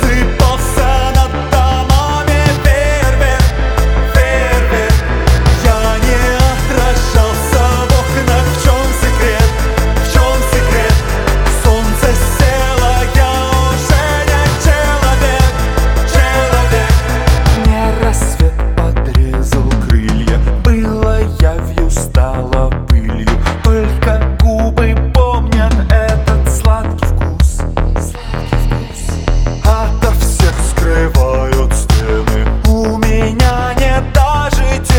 # New Wave